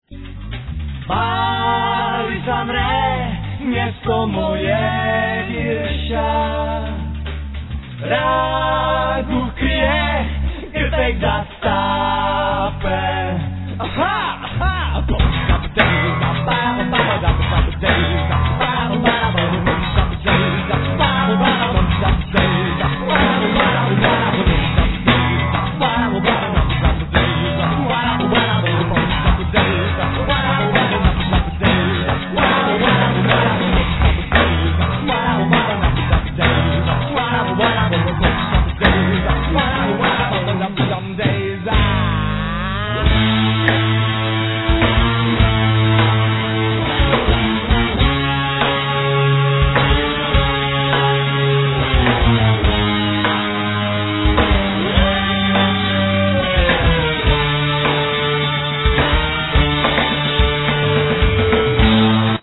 Voice, Chest Drum
Drums
Bass
Guitar
Cimbalum,Vocal
Percussions
Cello
Violin